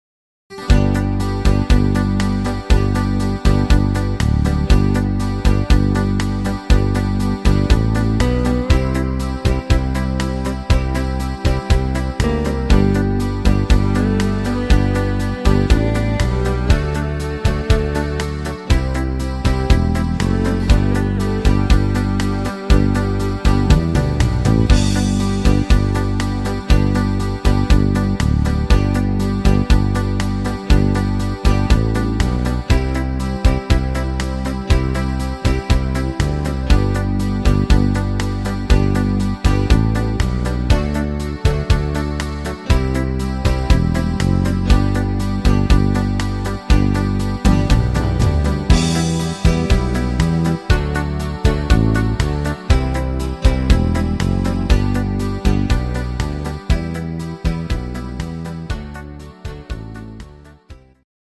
schöner melodischer Song